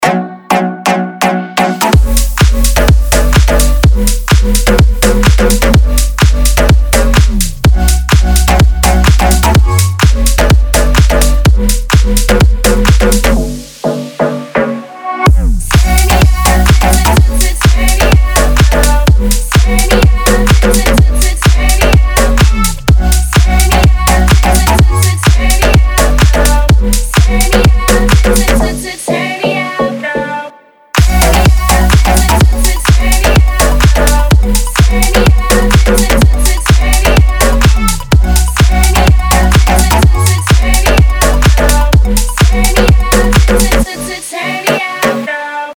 Клубные
Хаус-колбаса для твоего телефона!)